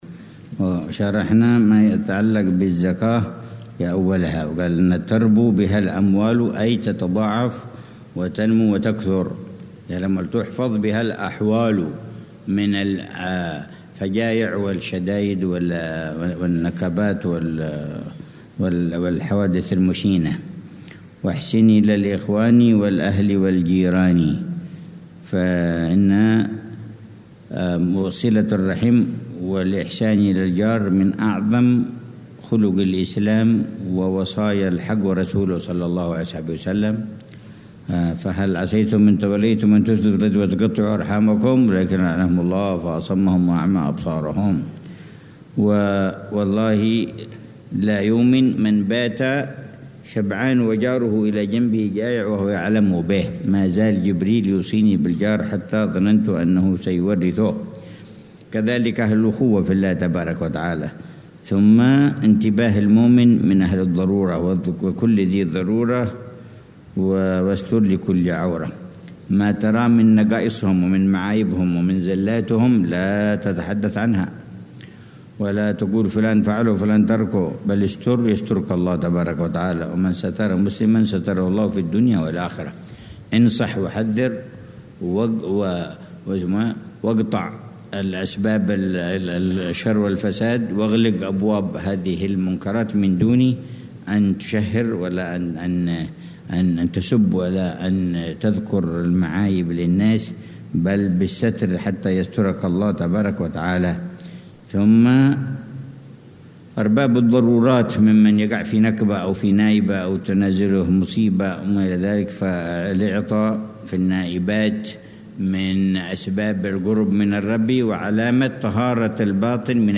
شرح الحبيب عمر بن حفيظ على منظومة «هدية الصديق للأخ والرفيق» للحبيب عبد الله بن حسين بن طاهر. الدرس الثالث والعشرون